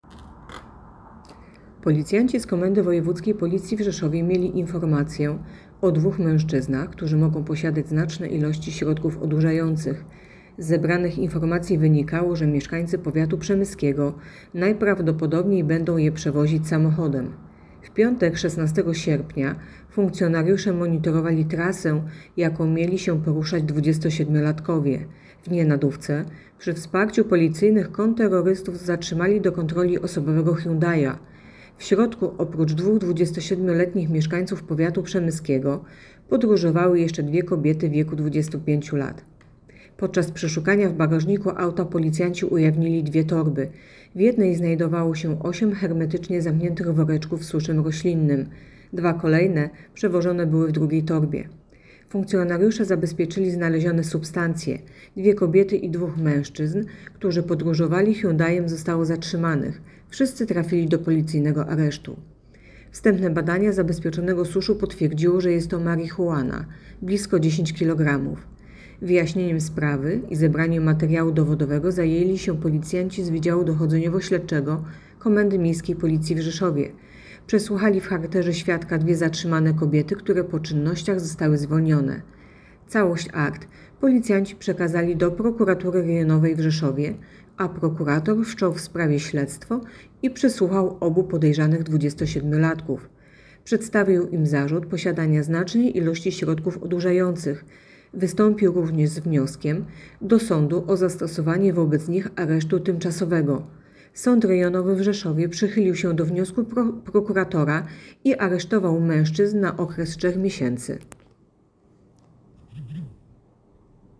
Nagranie audio Głos